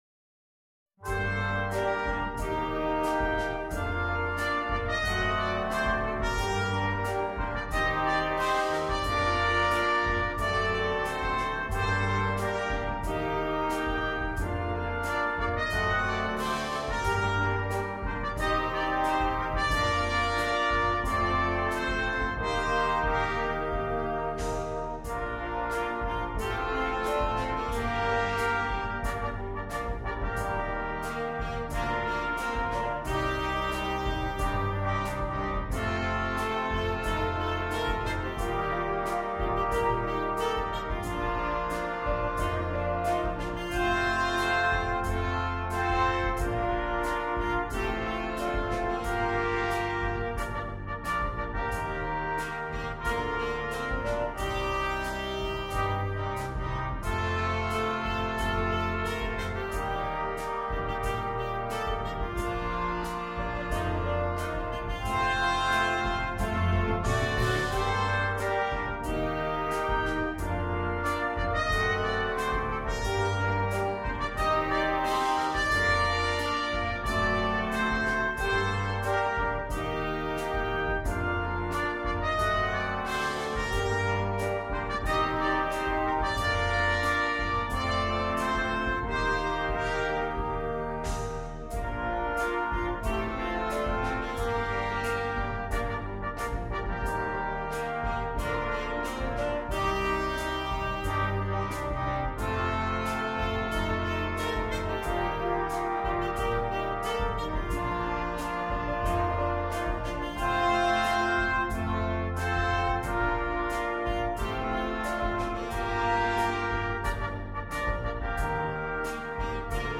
для брасс-бэнда